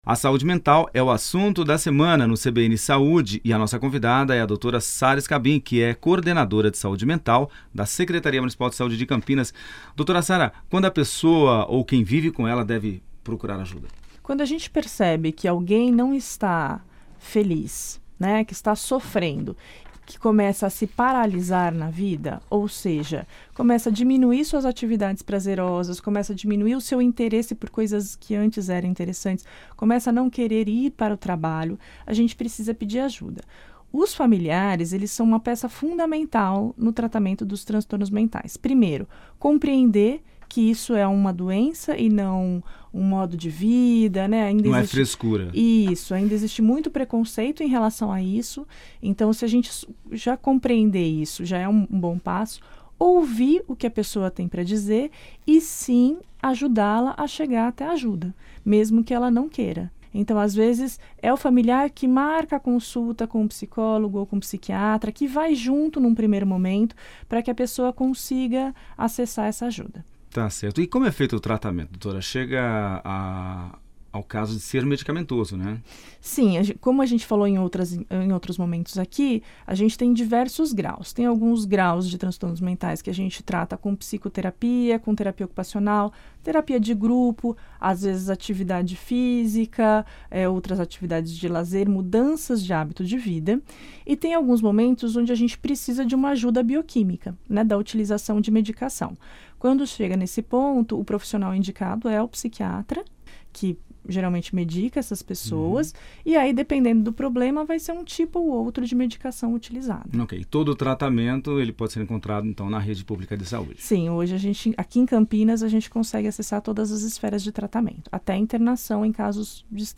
A Entrevista foi ao ar no dia 15 de Fevereiro de 2019